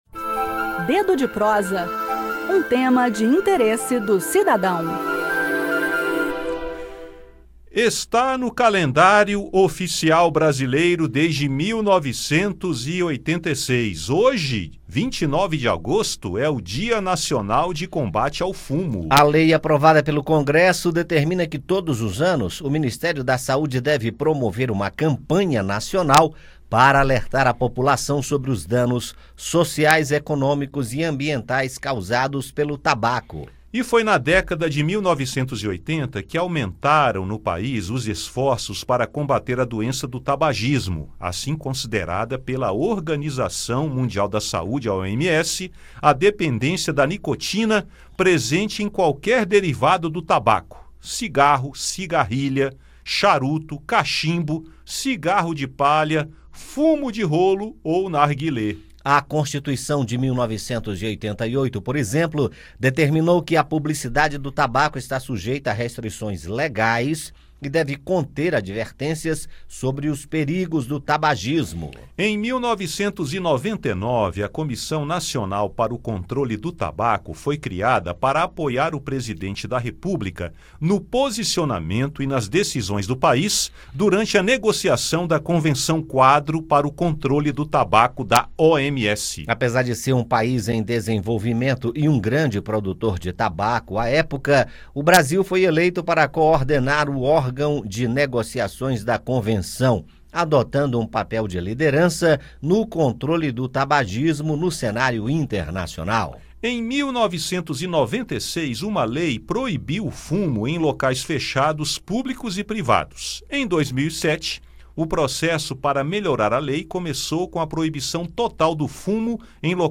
O assunto do bate-papo desta terça-feira (29) é o Dia Nacional de Combate ao Fumo, celebrado hoje, com destaque para lei de 1986 aprovada pelo Congresso Nacional que determina que, todos os anos, o Ministério da Saúde promova campanha nacional para alertar a população sobre os danos sociais, econômicos e ambientais causados pelo tabaco.